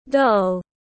Con búp bê tiếng anh gọi là doll, phiên âm tiếng anh đọc là /dɒl/
Doll /dɒl/